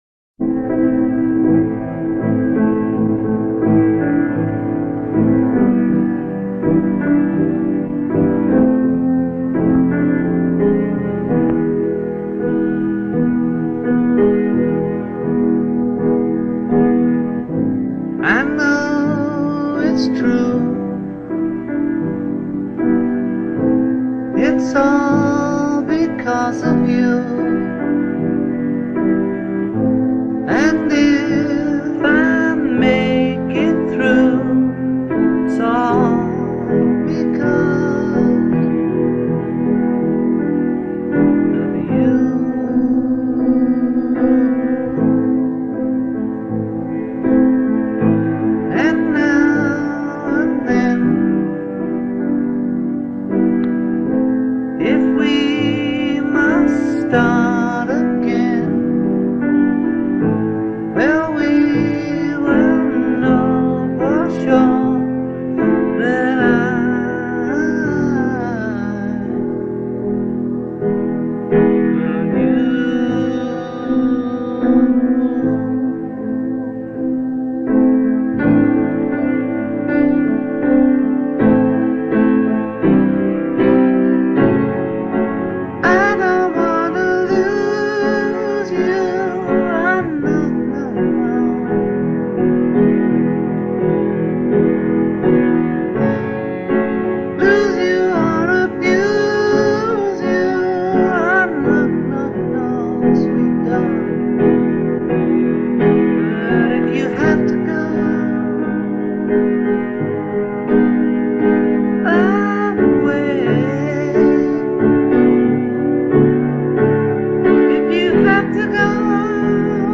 1977 Demo and 2023 Studio